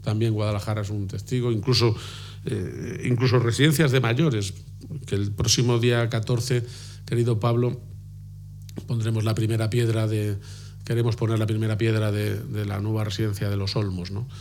>> García-Page anuncia la medida durante la inauguración del centro de datos de Fortinet en Torija, Guadalajara